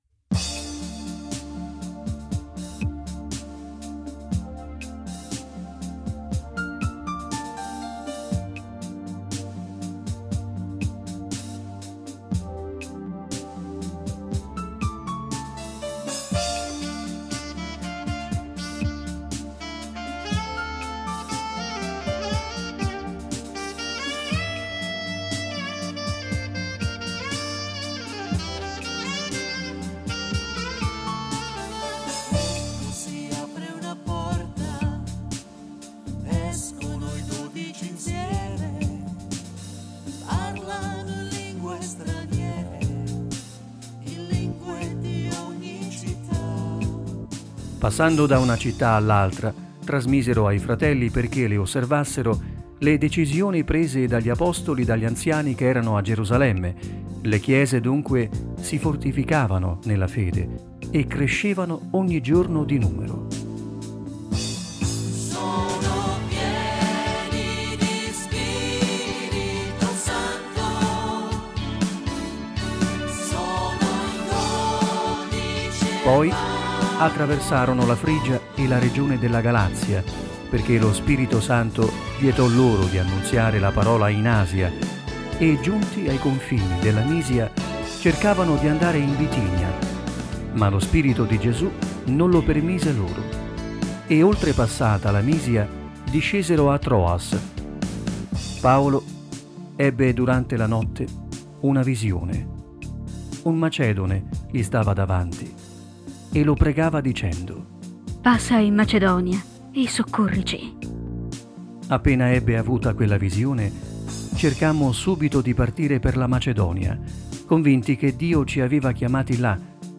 Letture della Parola di Dio ai culti della domenica